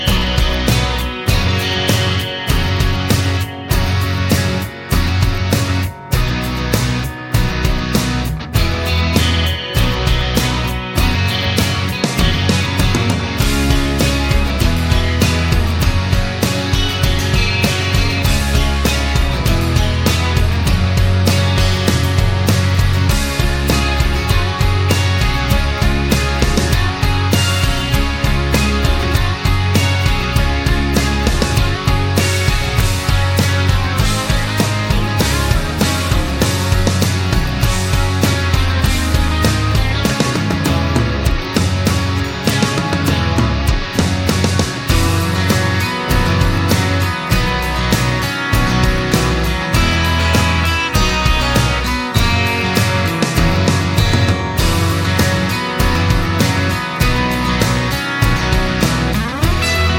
no Backing Vocals Indie / Alternative 3:08 Buy £1.50